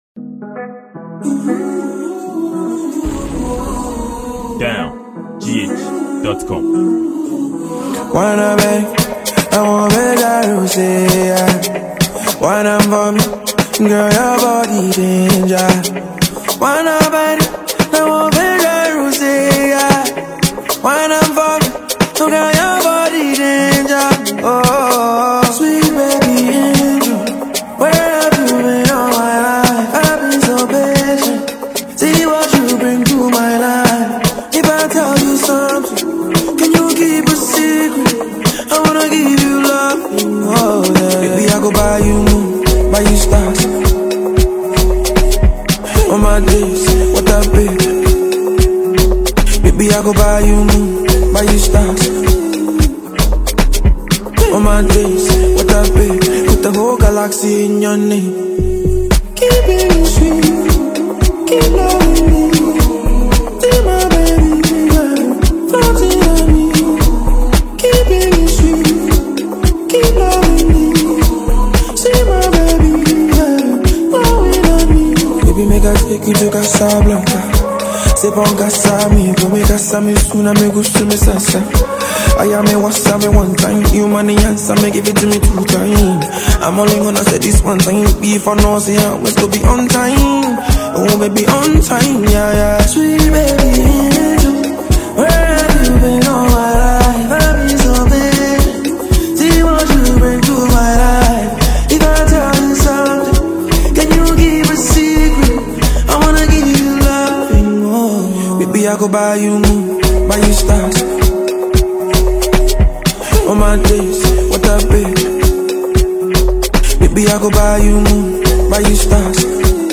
This is an afrobeats Ghana song banger for mp3 download.